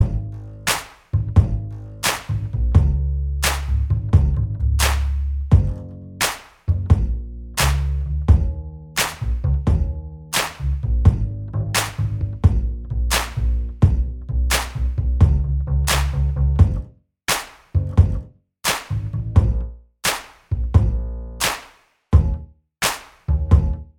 Minus All Guitars Pop (2010s) 3:06 Buy £1.50